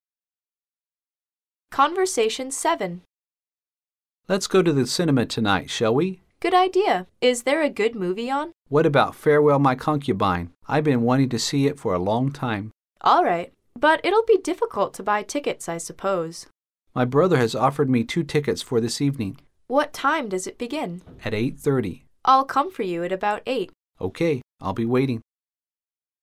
Conversation 7